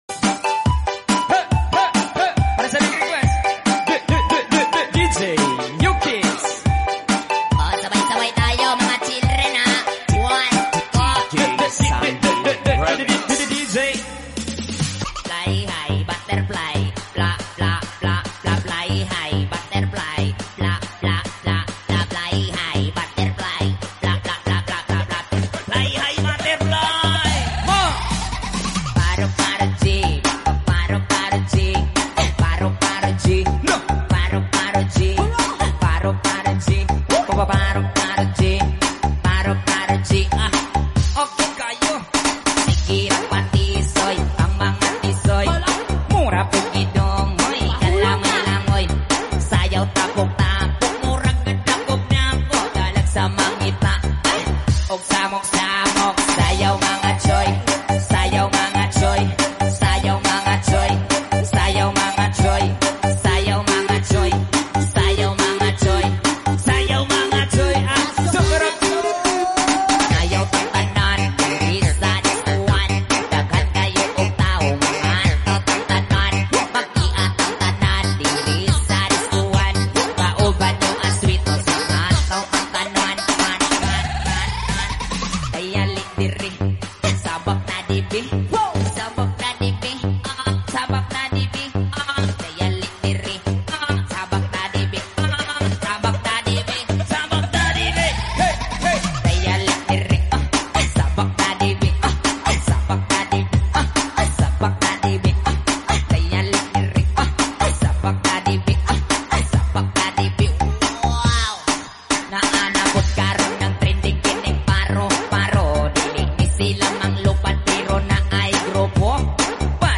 BUDOTS COVER